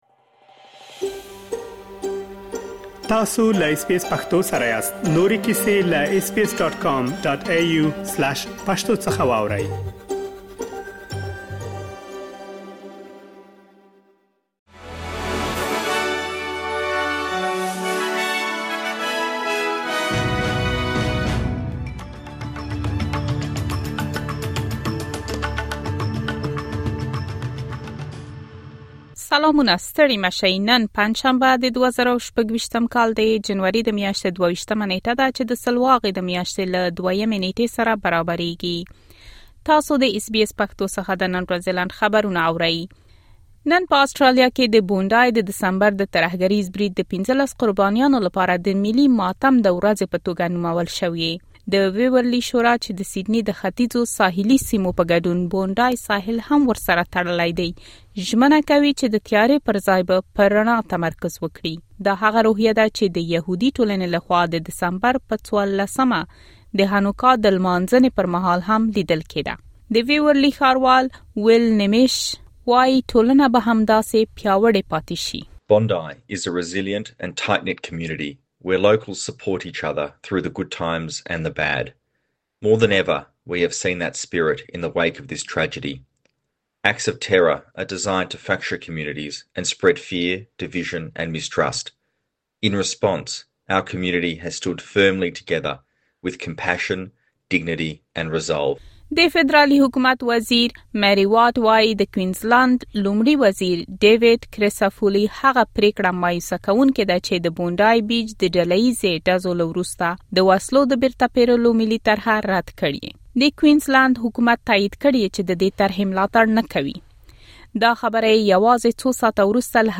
د اس بي اس پښتو د نن ورځې لنډ خبرونه |۲۲ جنوري ۲۰۲۶